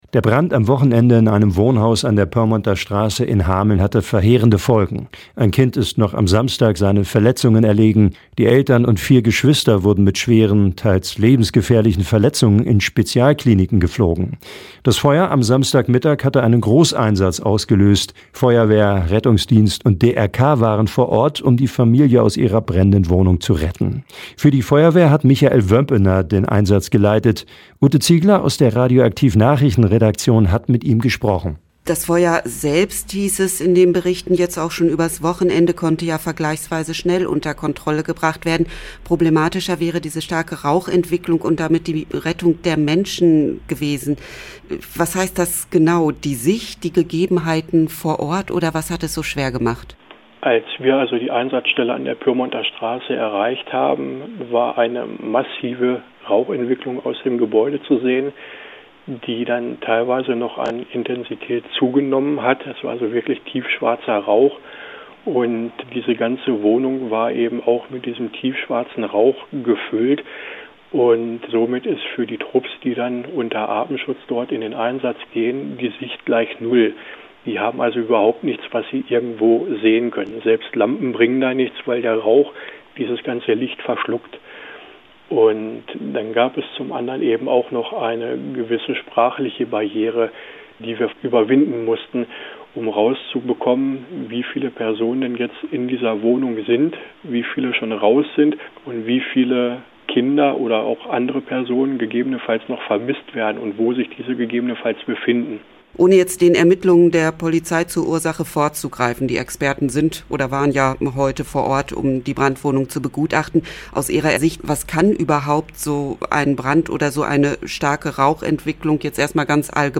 Aktuelle Lokalbeiträge Hameln: FEUERWEHR ZU EINSATZ Play Episode Pause Episode Mute/Unmute Episode Rewind 10 Seconds 1x Fast Forward 30 seconds 00:00 / Download file | Play in new window Der Brand am Wochenende in einem Wohnhaus an der Pyrmonter Straße in Hameln hatte verheerende Folgen.